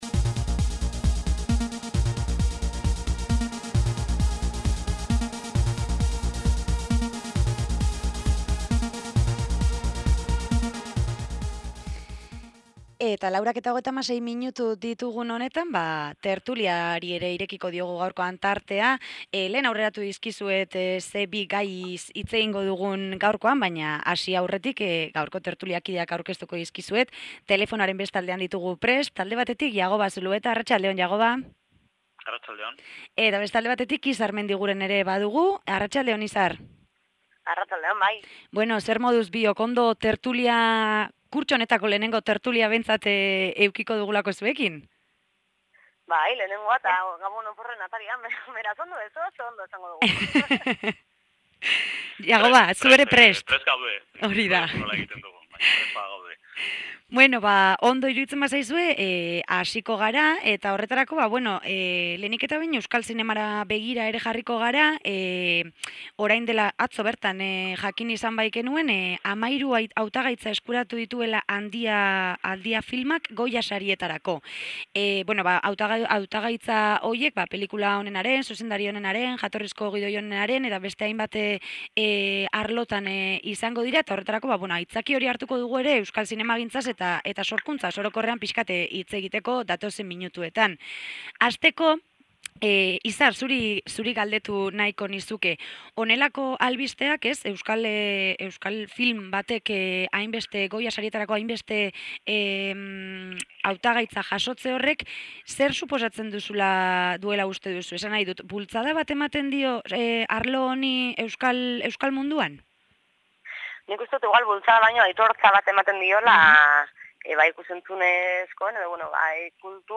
TERTULIA | Euskal zinemagintza eta literatura plagioak